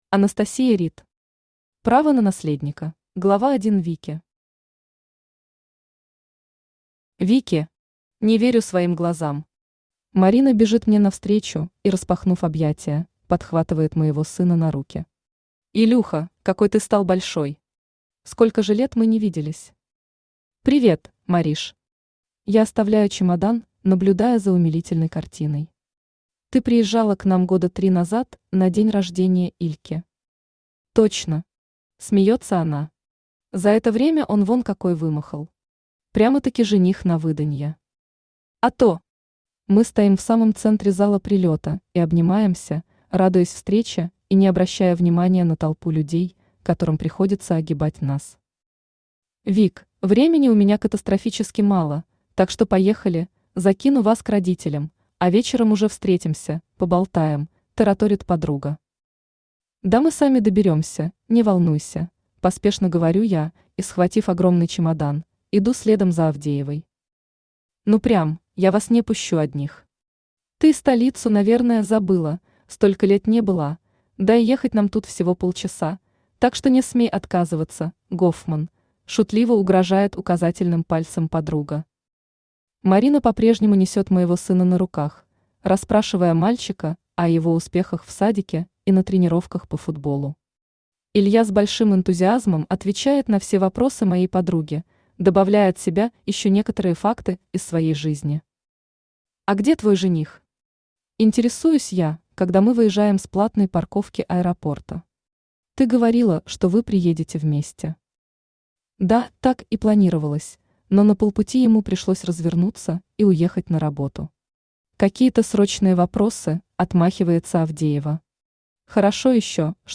Аудиокнига Право на наследника | Библиотека аудиокниг
Aудиокнига Право на наследника Автор Анастасия Ридд Читает аудиокнигу Авточтец ЛитРес.